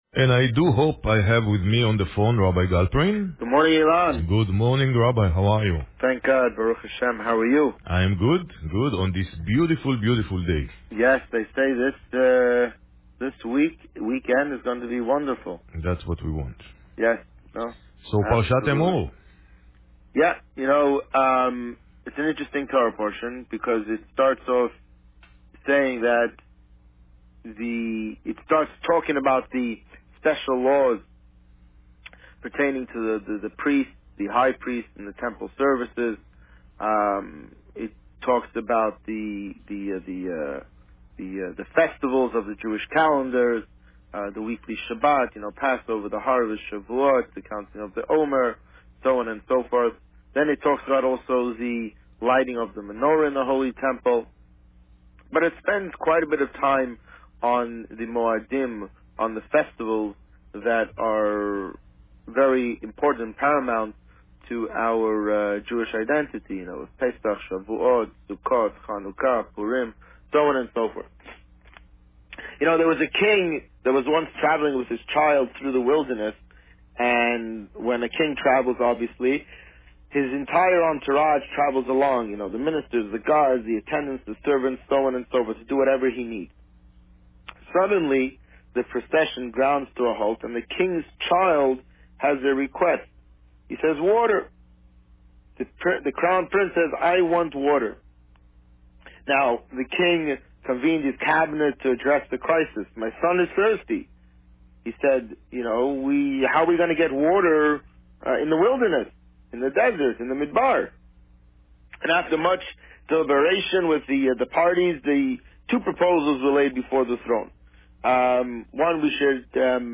On May 19, 2016, the Rabbi spoke about Parsha Emor. Listen to the interview here.